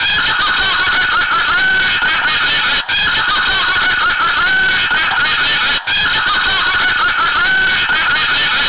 The final hardware mod involved the chip and speaker from a "talking" birthday card. Now the iMac laughs when the button is pushed.
Here's what it sounds like - BUT be warned: It's LOUD AND OBNOXIOUS!!
buttonlaugh.wav